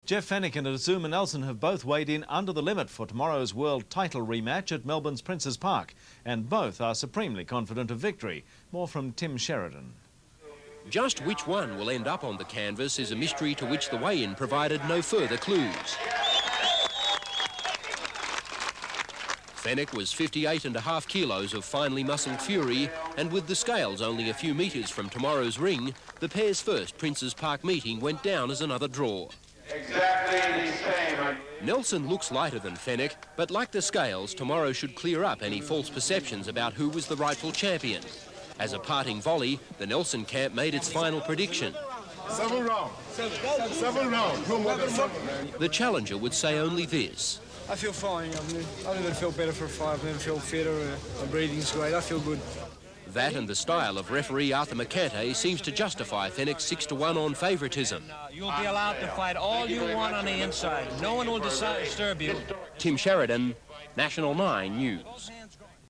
1992 news report Jeff Fenech sound effects free download